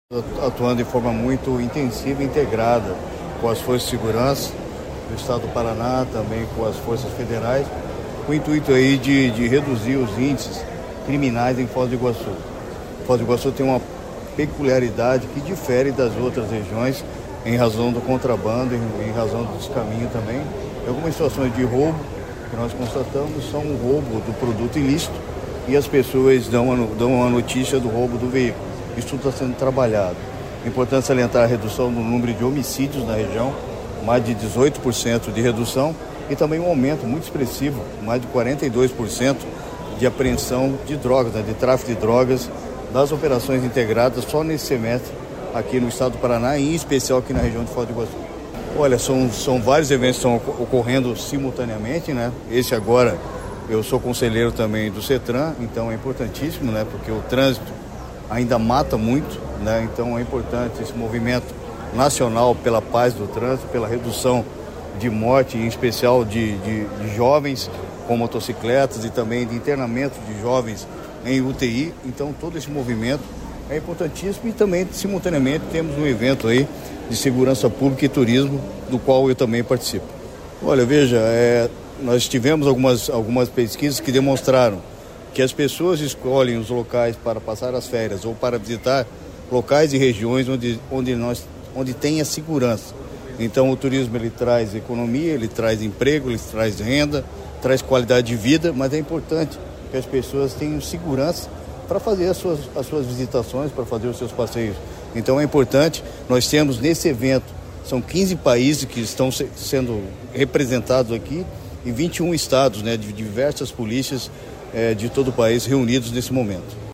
Sonora do secretário Estadual de Segurança Pública, Hudson Teixeira, sobre o Simpósio Nacional de Segurança no Atendimento ao Turista